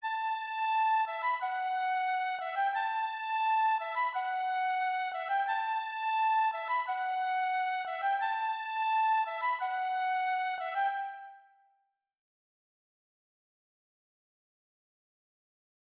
L’aboutissement de cette ascension nous amène au chiffre 3 à une cellule répétitive joué aux violons 1, clarinettes et piccolo.
motif-rc3a9pc3a9.mp3